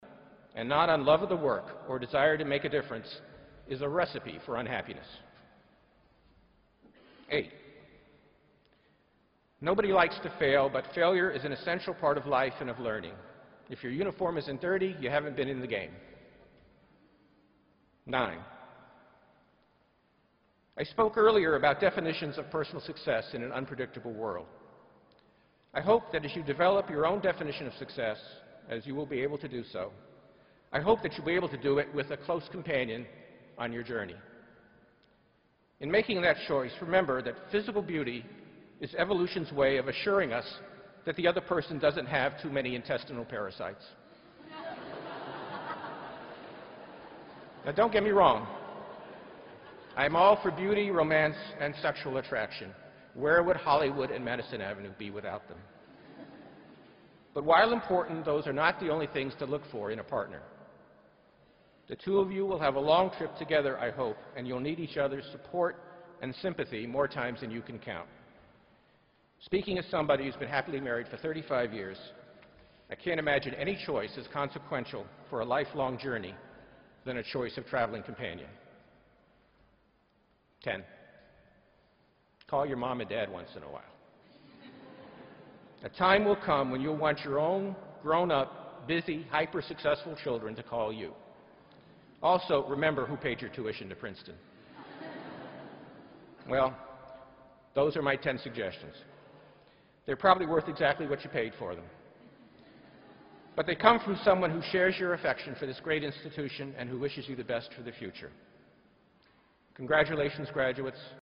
公众人物毕业演讲第310期:本伯南克2013普林斯顿(9) 听力文件下载—在线英语听力室